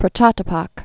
(prə-chätĭ-pŏk) 1893-1941.